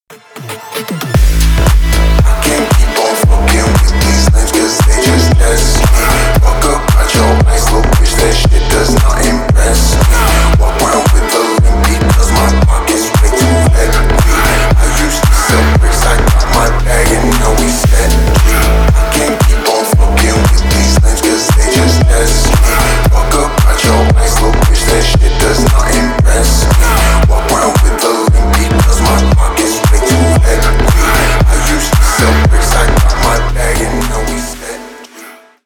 Танцевальные
громкие